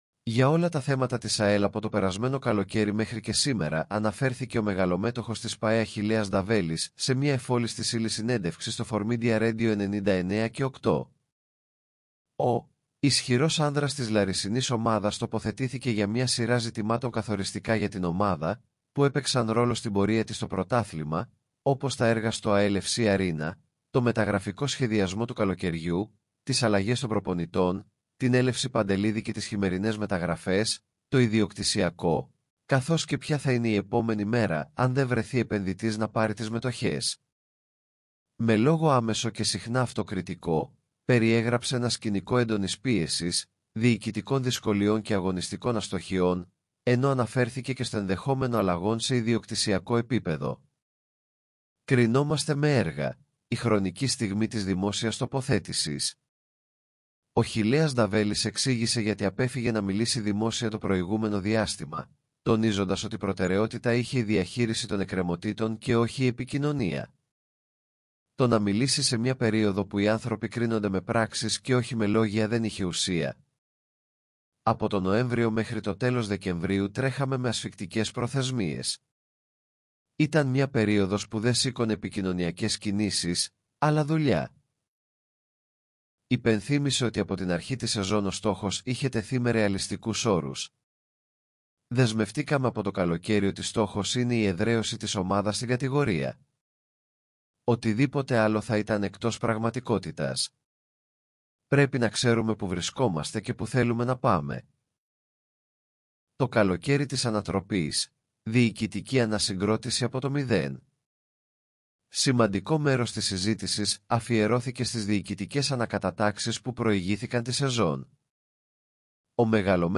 AI υποστηριζόμενο ηχητικό περιεχόμενο